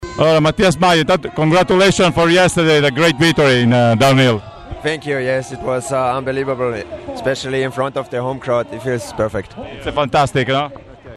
Intervista audio con Mathias Mayer